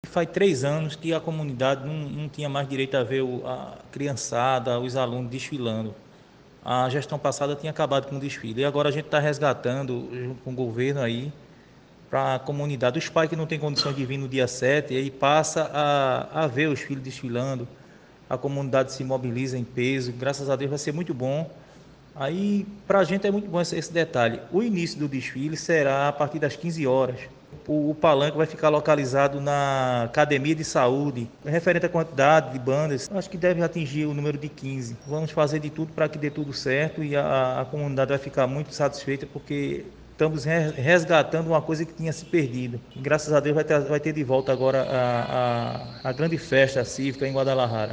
Ouça as informações com o vereador Júnior Dona: